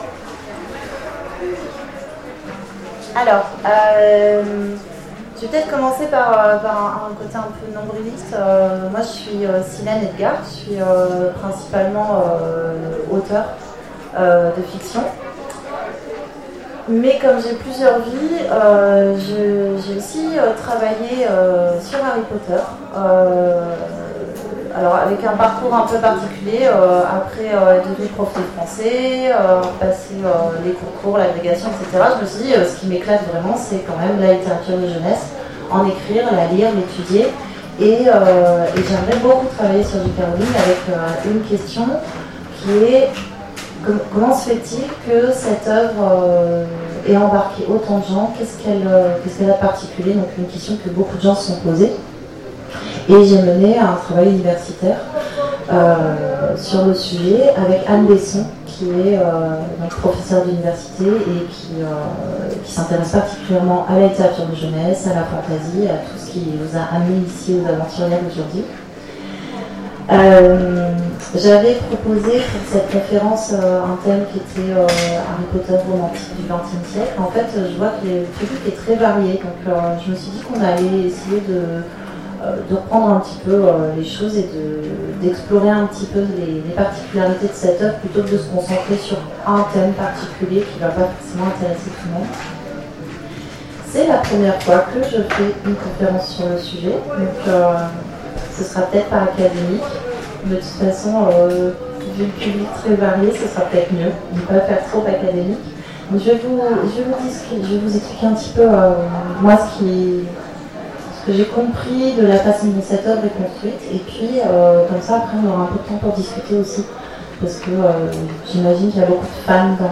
Aventuriales 2017 : Conférence la Saga Harry Potter
Aventuriales_2017_table_ ronde_harry_potter_ok.mp3